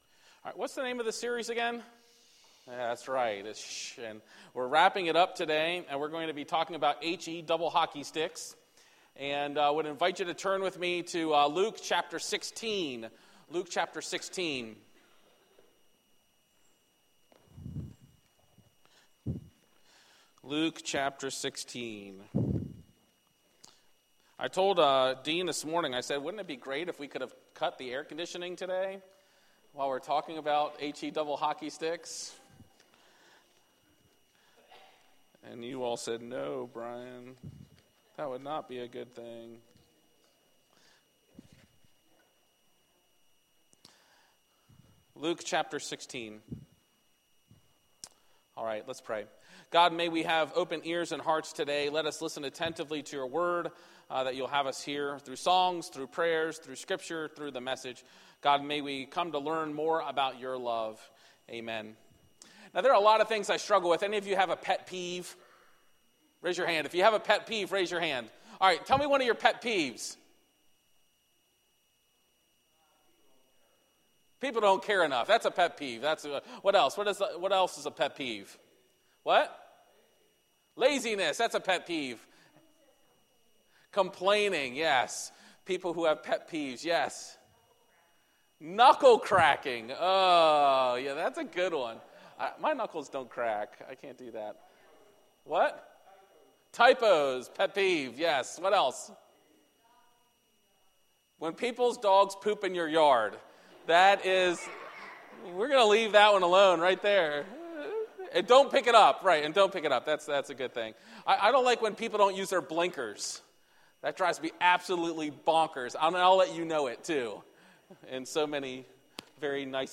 A message from the series "Shhh…."